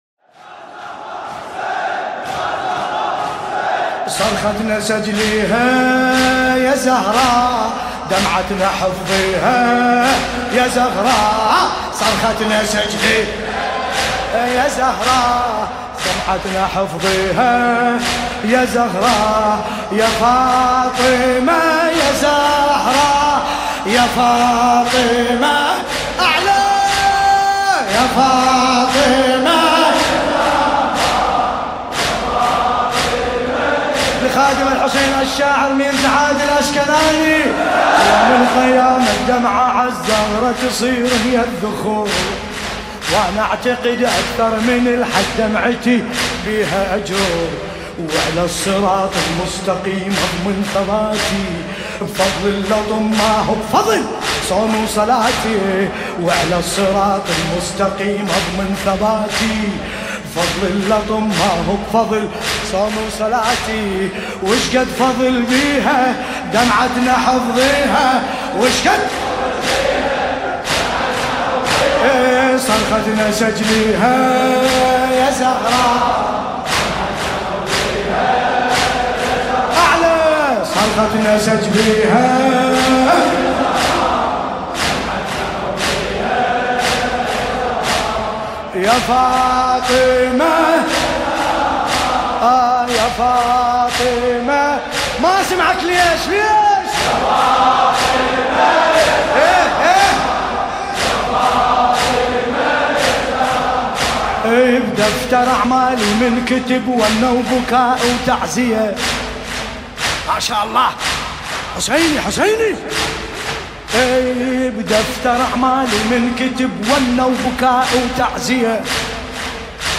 الأيام الفاطمية 1437 هـ - البصرة
لطمية